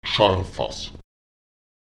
Lautsprecher jarvas [ÈtSarfas] das Trinkgefäß